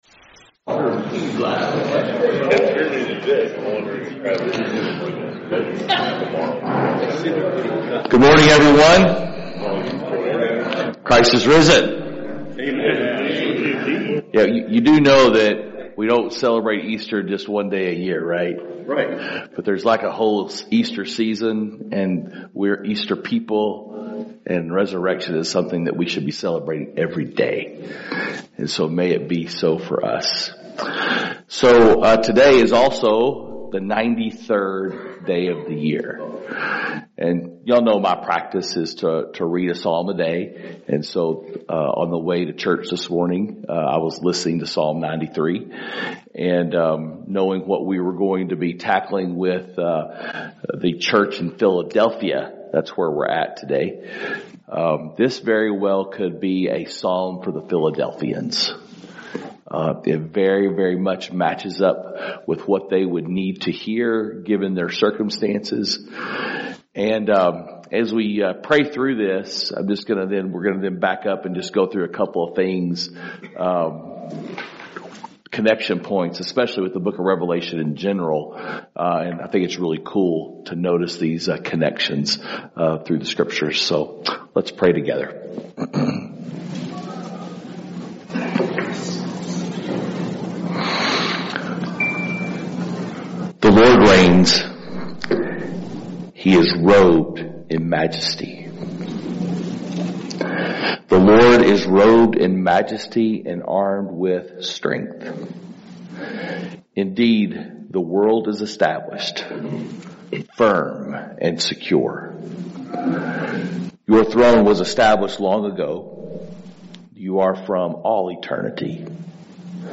Men’s Breakfast Bible Study 4/2/24